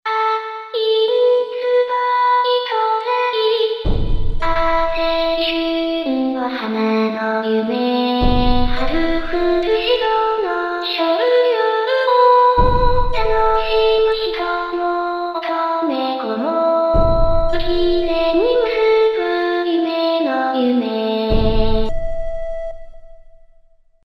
逍遙歌第二の一番のみ」（ビブラートつき）